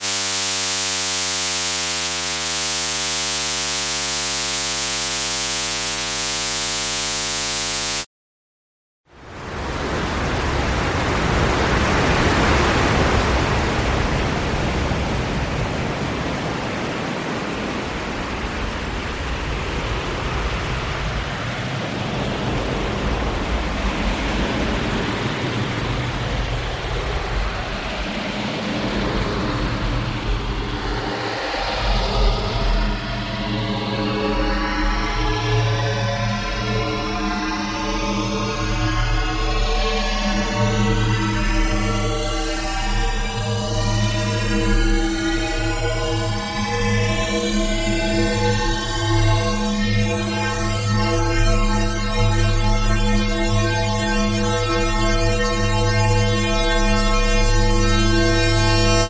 ; multiple oscillators, mixed down to mono
; a down gliss
; multiple noise instances, amplitude modulated
; filter noise with bandpass